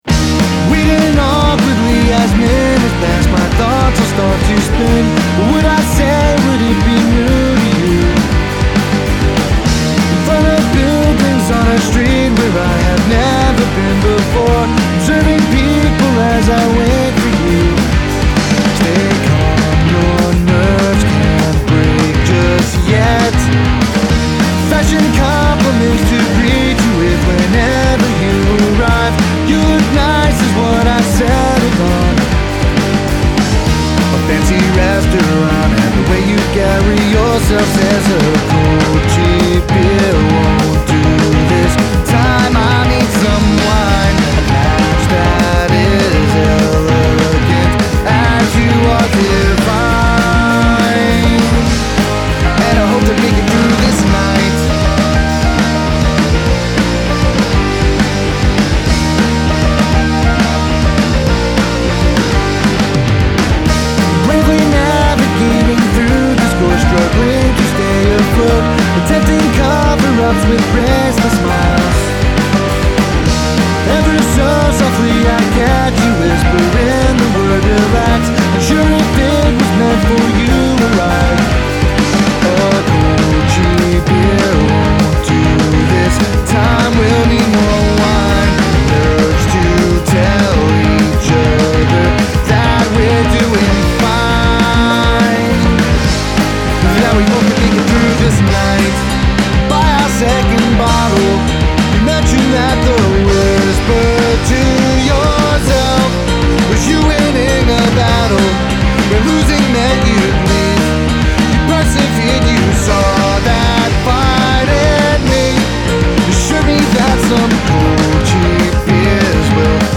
Vocals, Guitar, Drums